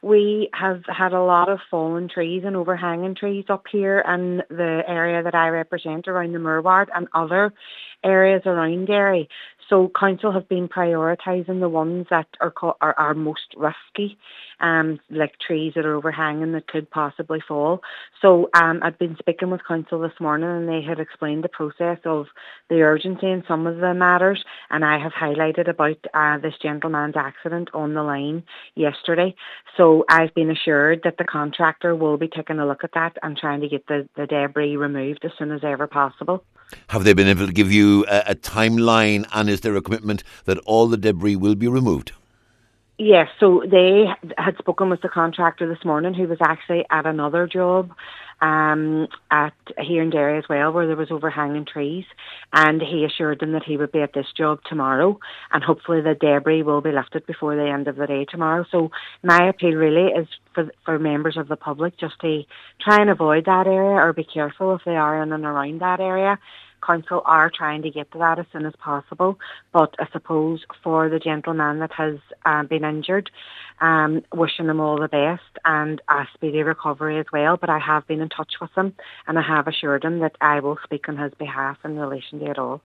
Councillor Hutton says she understands that overhanging trees are being prioritised: